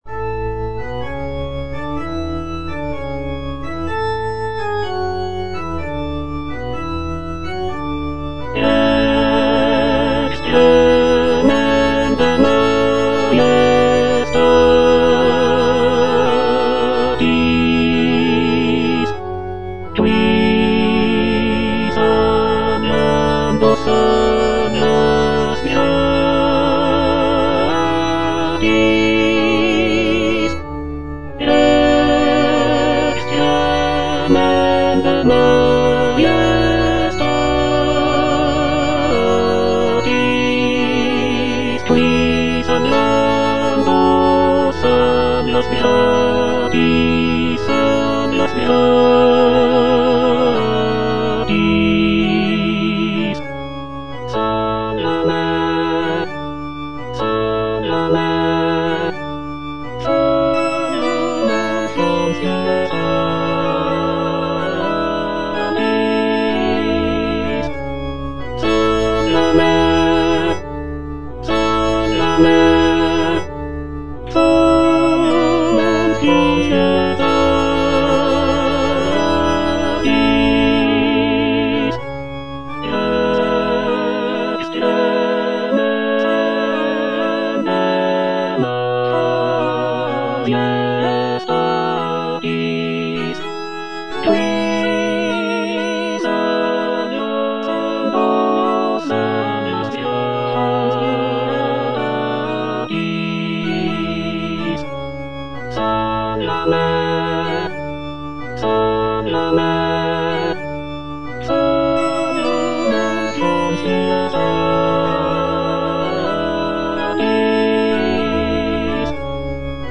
F. VON SUPPÈ - MISSA PRO DEFUNCTIS/REQUIEM Rex tremendae - Tenor (Emphasised voice and other voices) Ads stop: auto-stop
The piece features lush harmonies, soaring melodies, and powerful choral sections that evoke a sense of mourning and reverence.